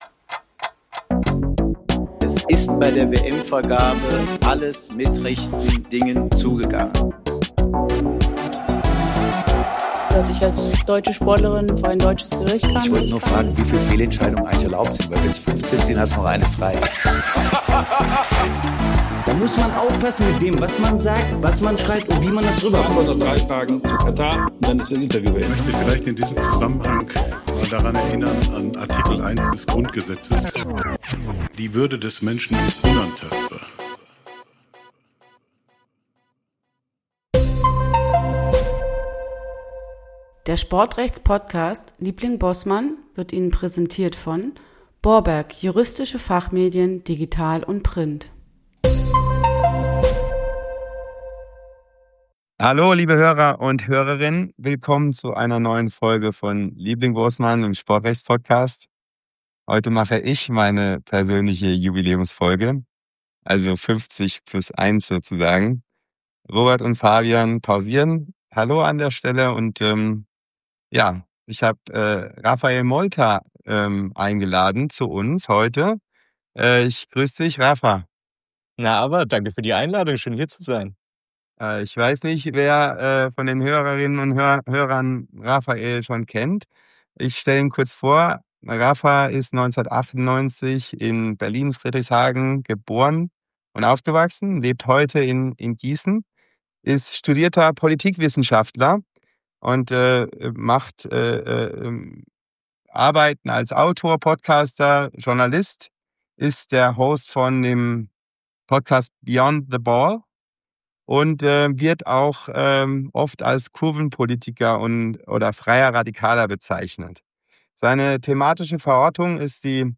Profifußball auf dem Prüfstand: Ultras, VAR und Sicherheitsdiskurs - ein Gespräch über Fankultur als Ausdrucksform.